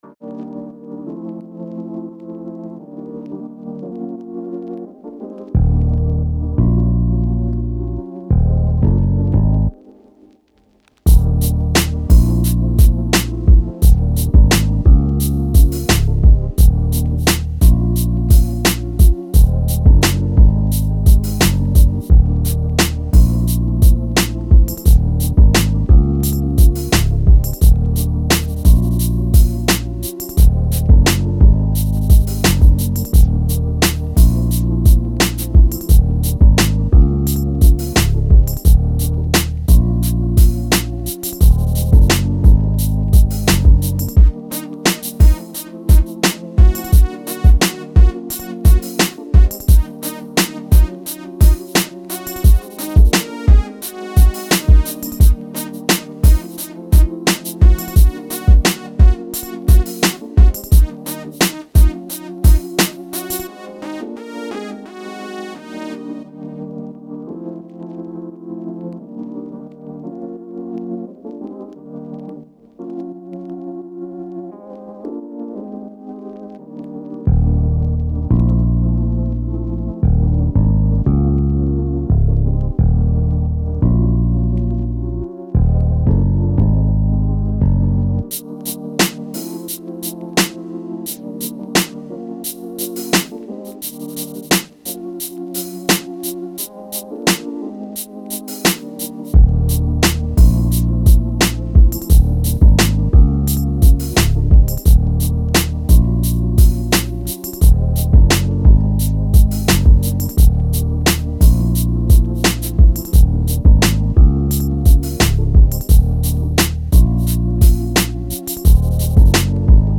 Energetic, Positive, Sexy
Acoustic Guitar, Drum, Heavy Bass, Piano, Strings